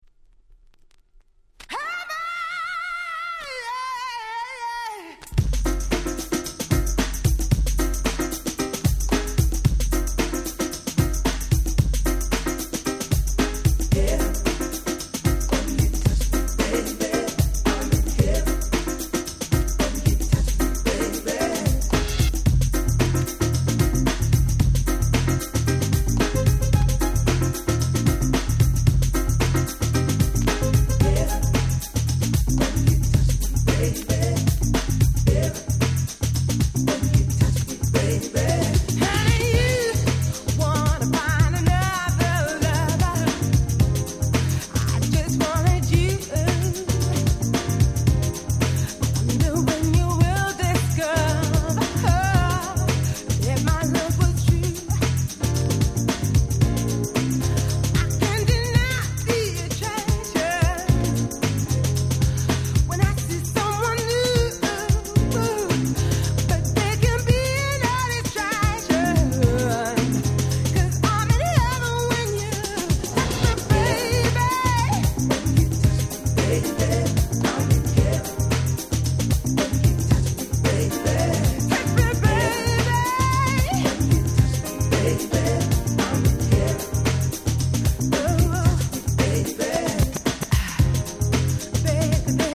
90' UK Soul Classics !!